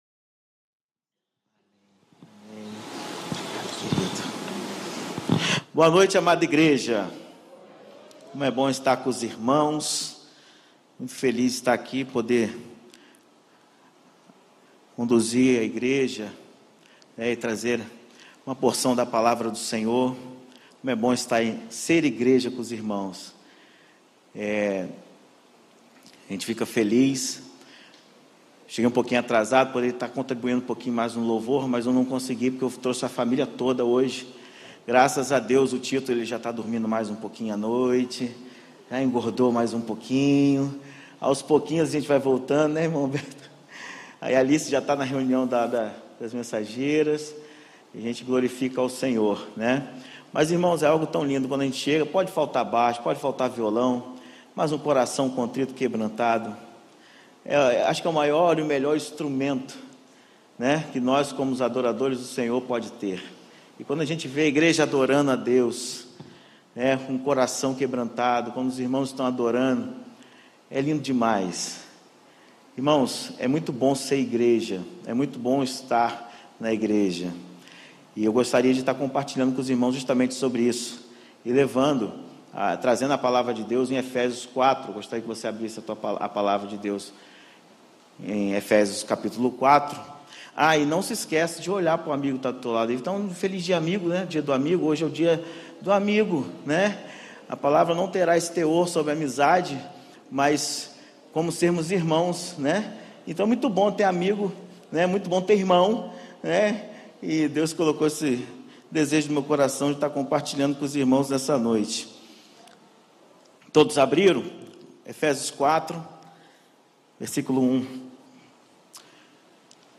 Primeira Igreja Batista do IPS